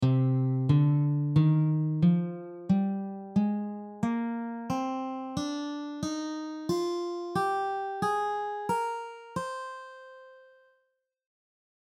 Each scale below will cover two octaves on the guitar in standard notation with tabs and audio examples included.
C minor scale
The notes of the C natural minor scale are C, D, Eb, F, G, Ab, and Bb.
C-minor-Cm-scale-audio.mp3